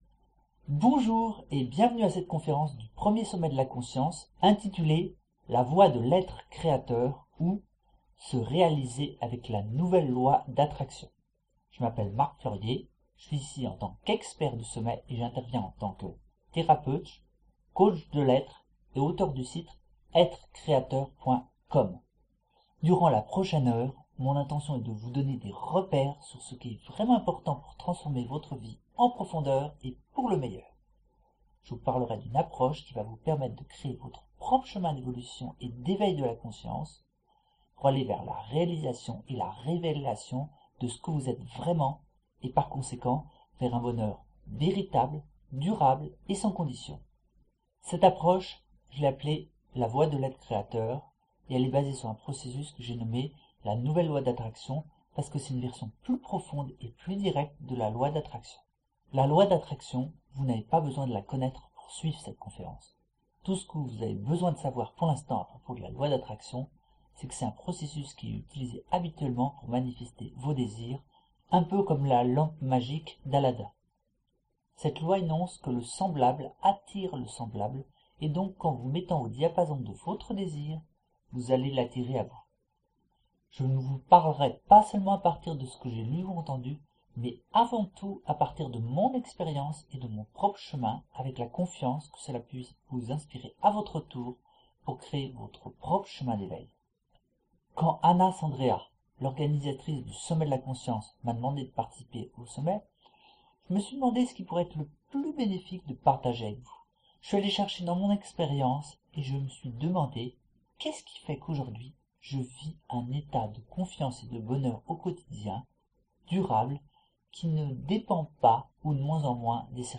Conference - La Voie de l Etre Createur - Se Realiser avec la Nouvelle Loi d Attraction.mp3